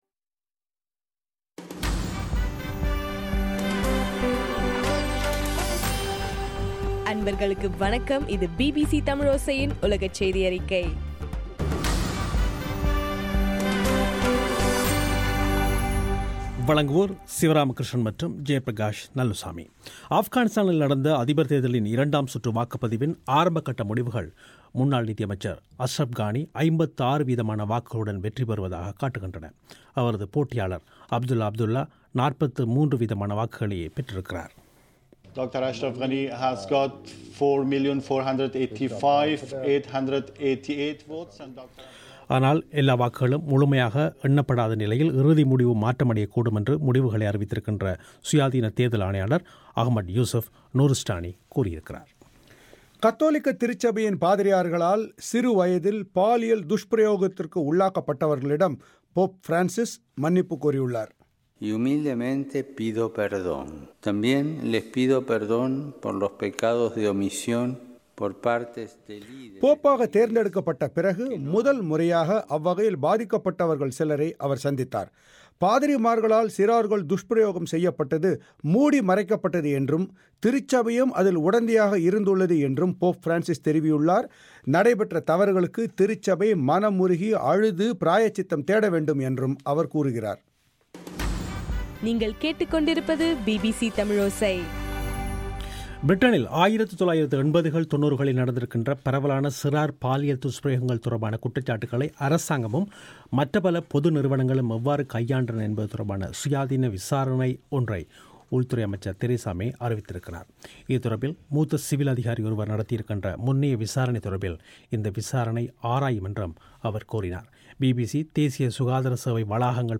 பிபிசி தமிழோசையின் உலகச் செய்தியறிக்கை- ஜூலை-07